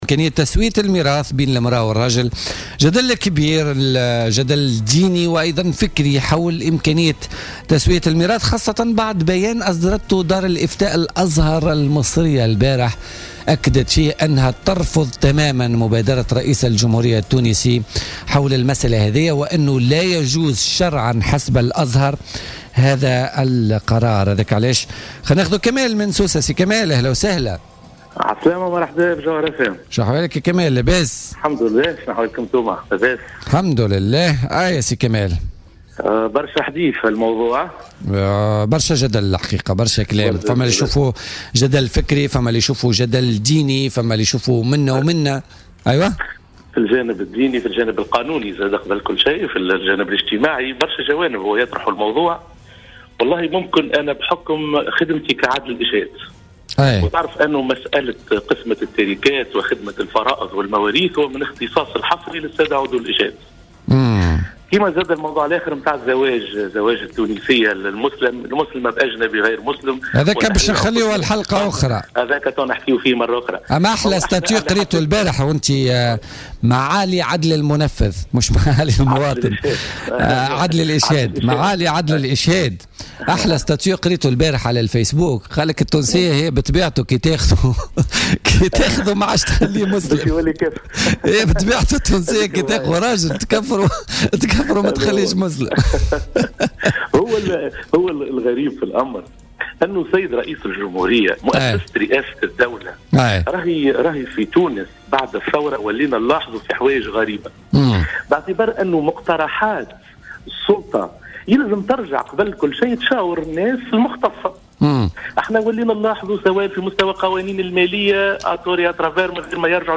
وأوضح في اتصال هاتفي بـ "الجوهرة اف أم" من خلال برنامج "بوليتيكا"، أن الميراث لا يقوم في واقع الأمر على معيار الجنس في المطلق حسب علم الميراث.